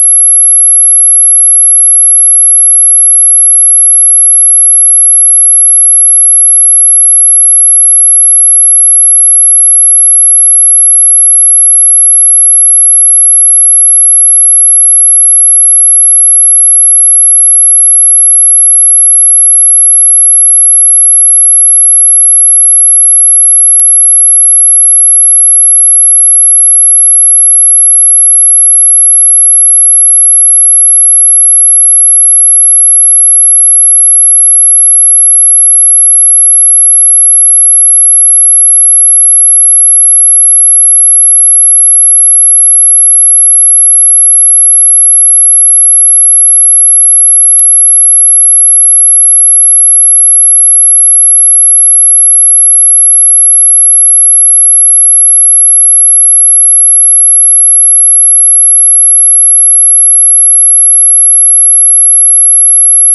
High Pitch Dog Whistle
Super high pitch dog whistle sound effect for 30 seconds. This file is super high pitch and your dog will hate you if you play this for too long.
Very annoying.